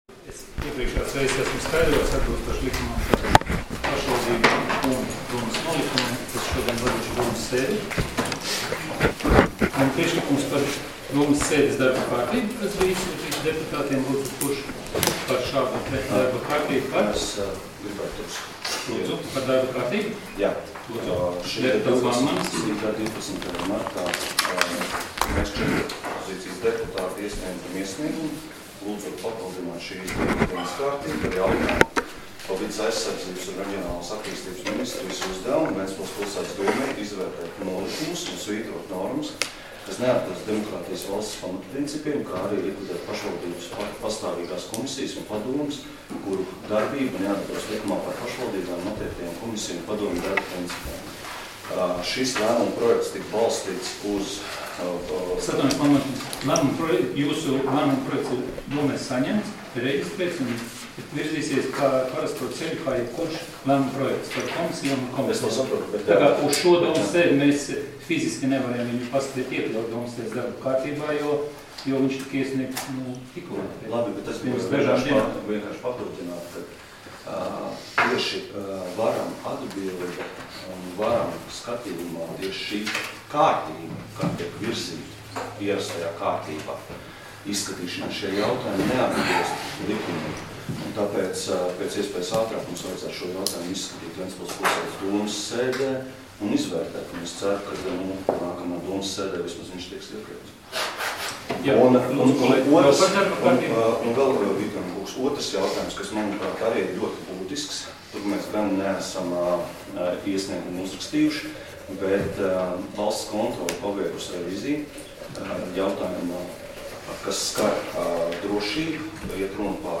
Domes sēdes 16.03.2018. audioieraksts